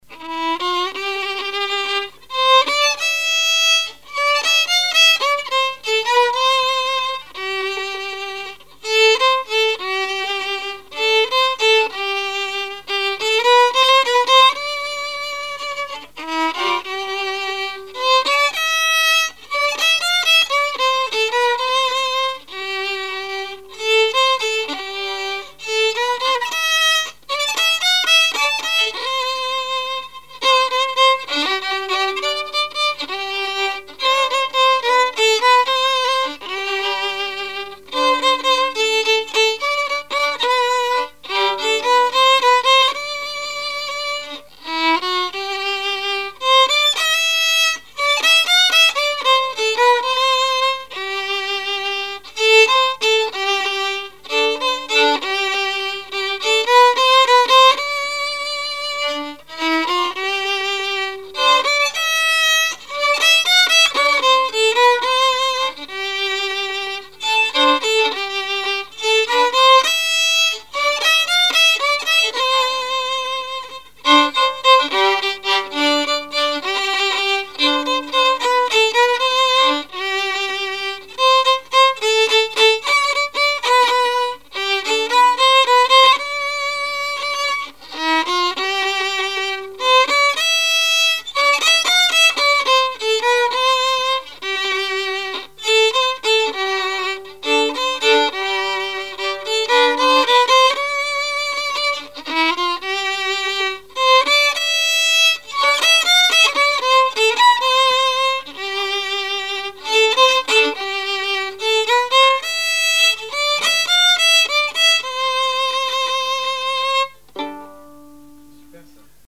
Mémoires et Patrimoines vivants - RaddO est une base de données d'archives iconographiques et sonores.
musique varieté, musichall
Genre strophique
Répertoire musical au violon